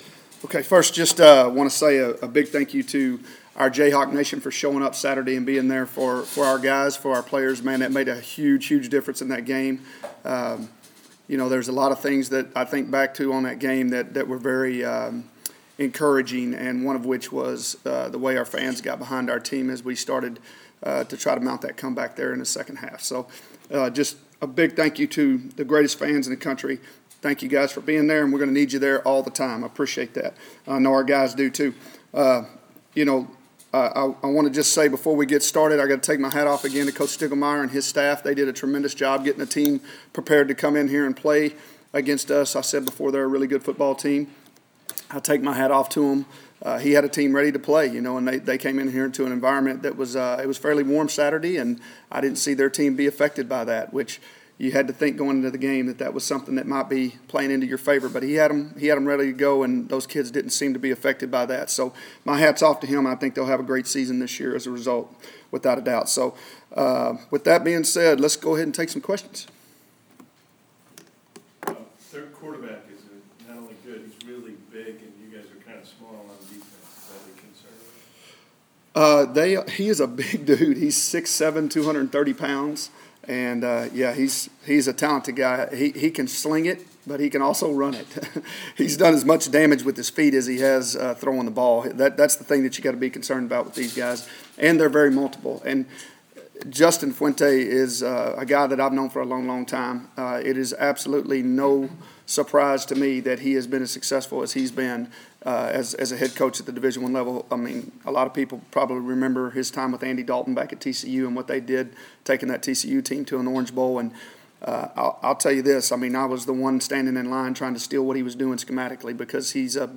A few days removed from losing his first game as Kansas football coach to South Dakota State, David Beaty speaks with media members Sept. 8, 2015, about the ramifications of that setback, what KU’s coaches saw when reviewing video from the season opener, and how the Jayhawks can improve their problem areas against Memphis.